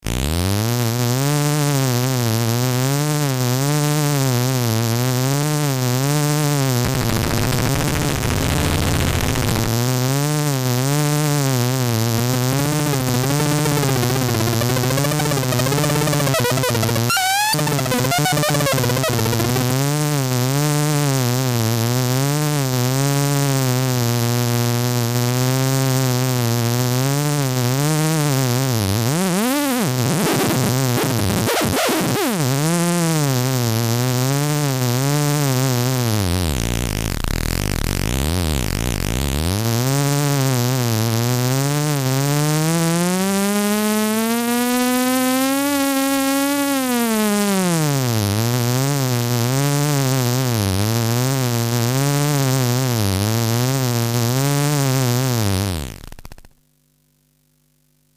A pitch matching/shaping/warping 14 hp module using granular technology.
The voice sampling are connected to input 1 and the testwave to input 2. The wave will follow the pitch of the voice. The controls are tweaked to obtain more or less precise pitch tracking, and to create some effects: